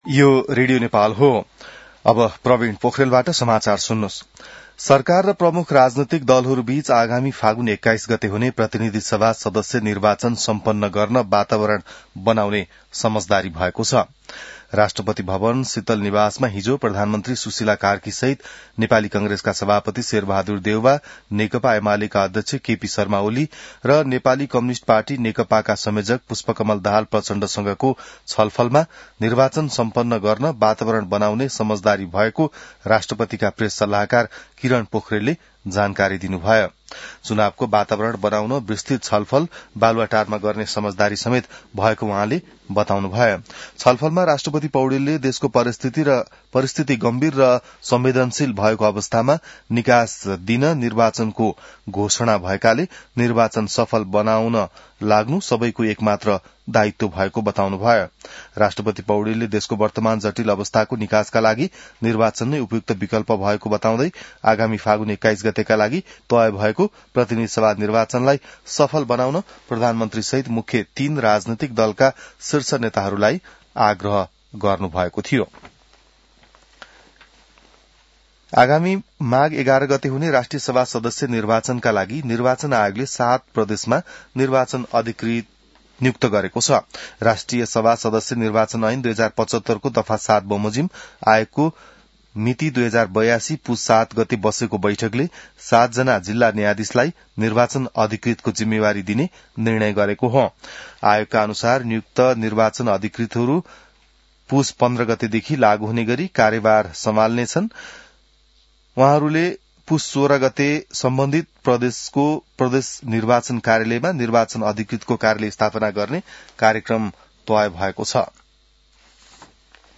बिहान ६ बजेको नेपाली समाचार : ९ पुष , २०८२